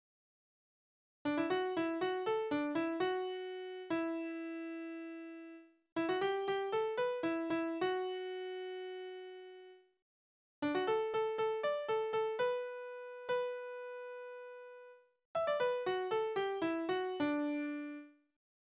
Naturlieder
Tonart: D-Dur
Taktart: 6/8
Tonumfang: große None
Besetzung: vokal